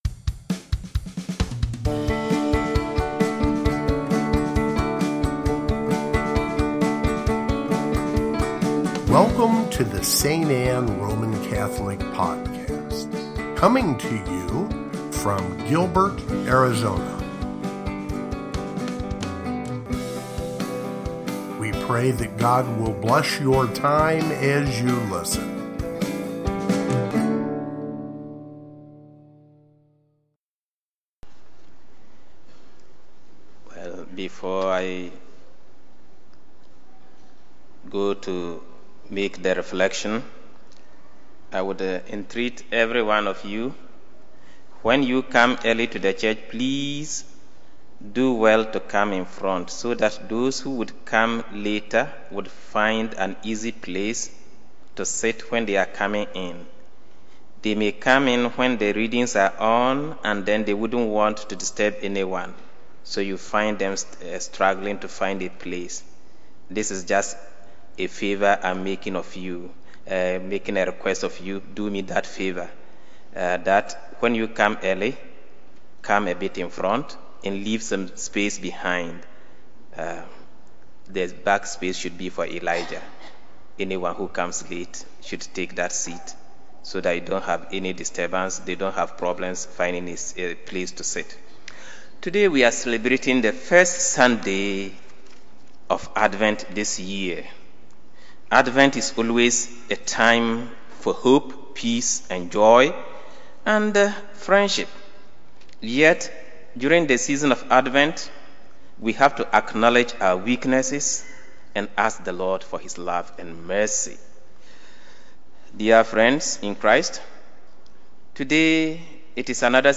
First Sunday of Advent (Homily) | St. Anne